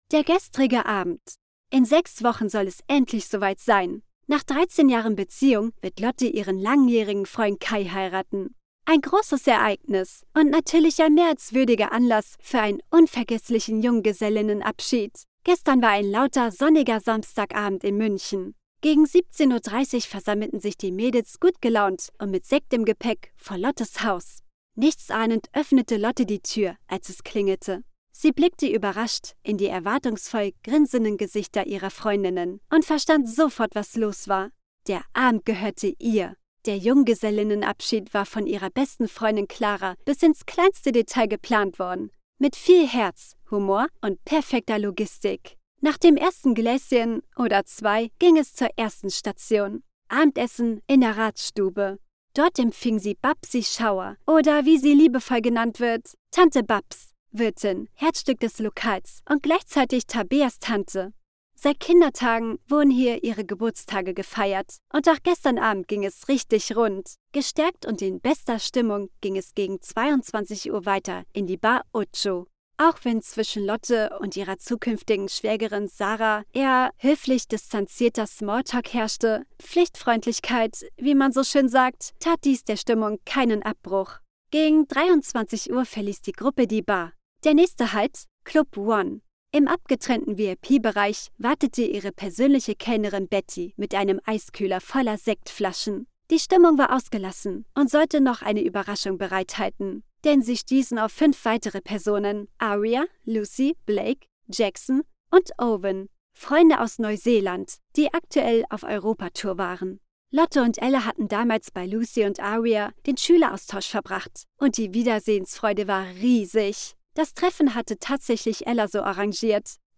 My voice is a blend of youthful energy and seasoned charm, ensuring each text resonates fully. Warm, soothing, witty, and versatile, I infuse vibrancy into every word.
0806Audiobook.mp3